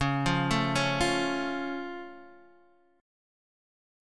C#+ chord